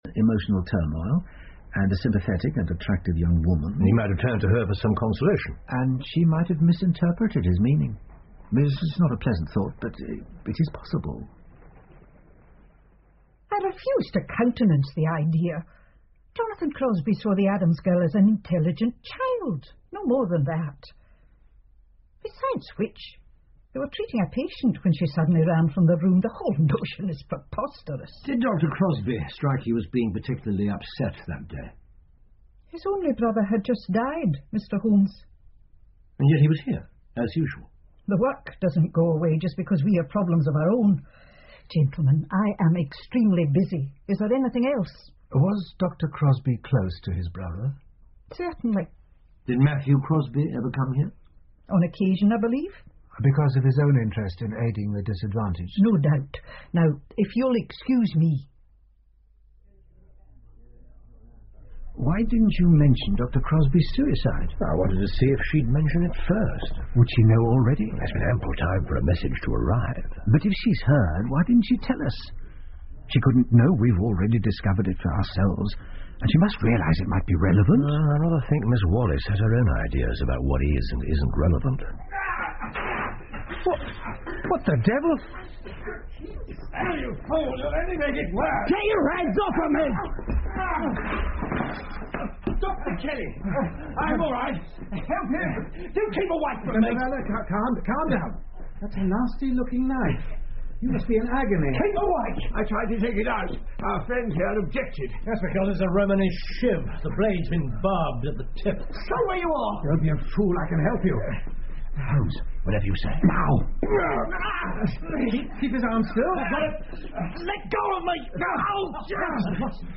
福尔摩斯广播剧 The Tragedy Of Hanbury Street 5 听力文件下载—在线英语听力室